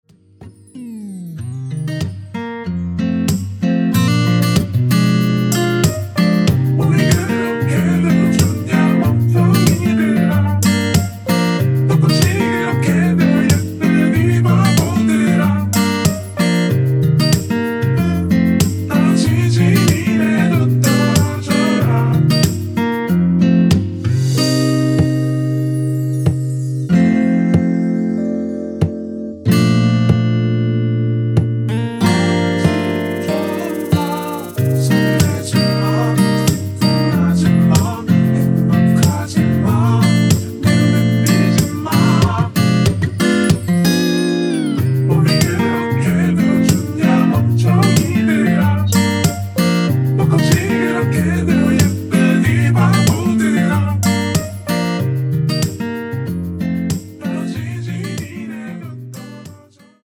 원키에서(-3)내린 코러스 포함된 MR 입니다.
Bb
앞부분30초, 뒷부분30초씩 편집해서 올려 드리고 있습니다.
중간에 음이 끈어지고 다시 나오는 이유는